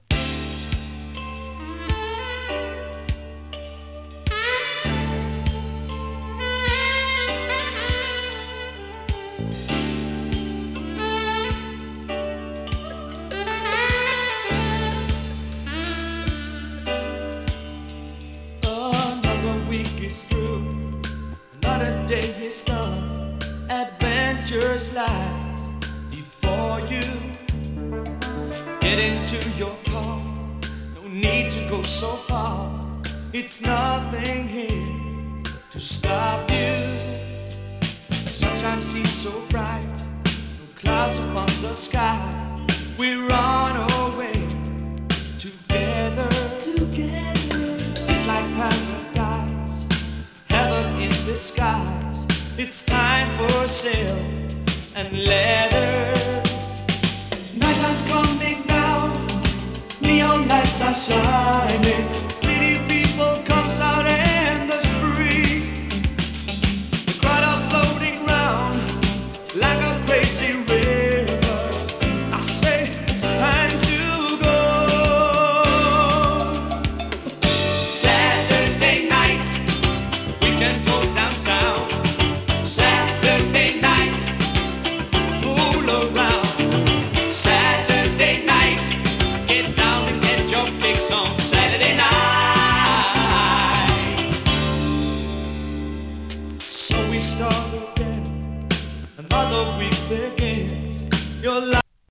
北欧産AOR